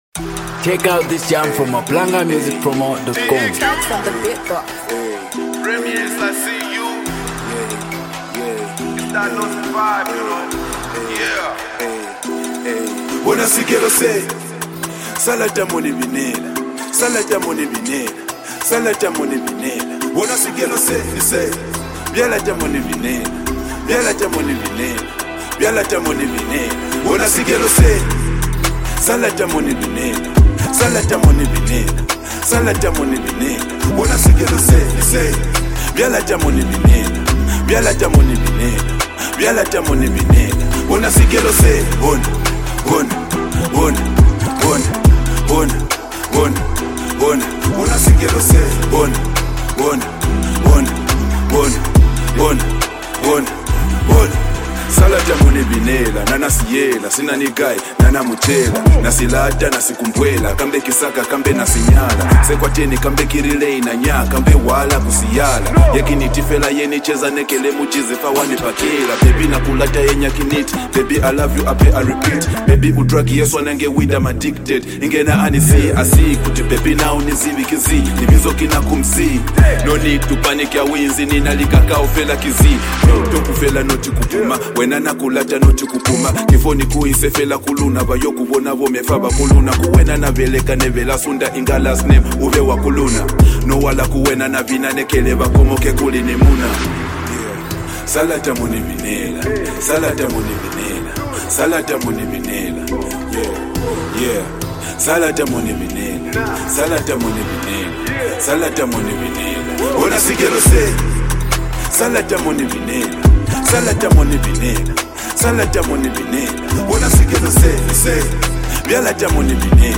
vibrate dancehall sound